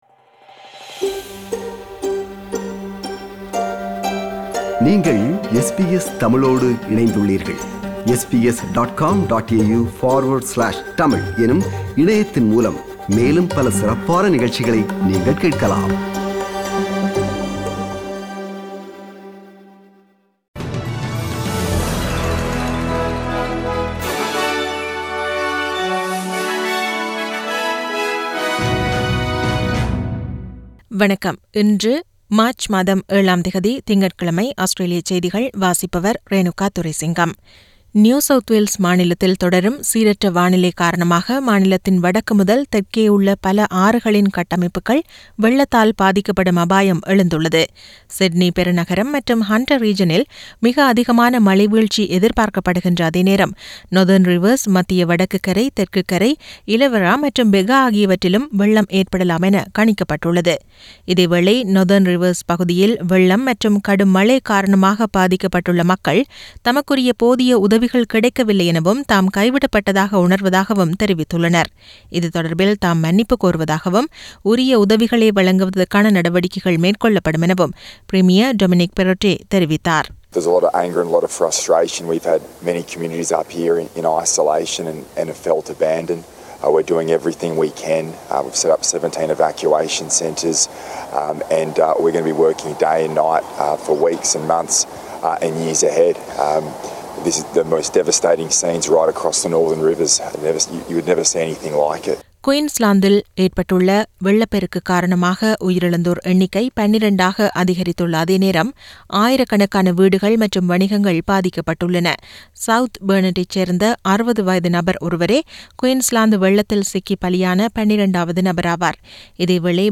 Australian news bulletin for Monday 07 Mar 2022.